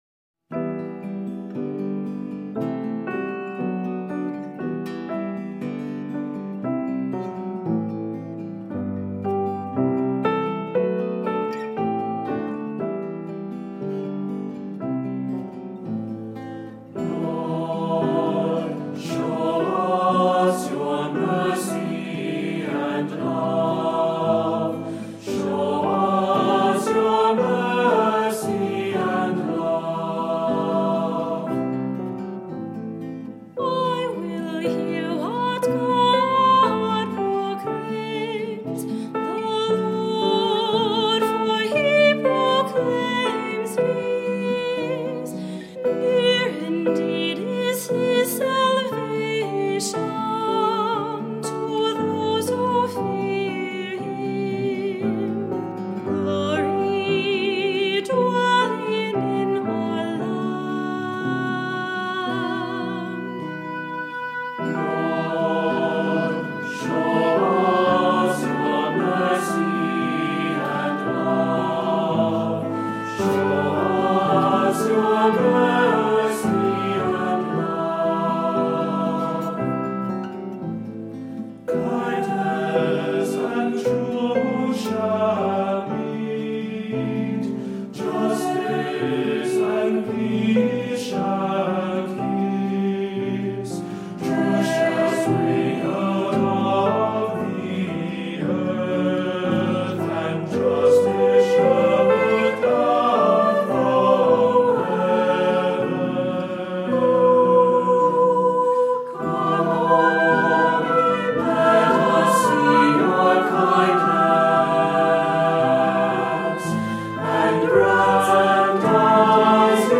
Voicing: Unison; Cantor; Descant; Assembly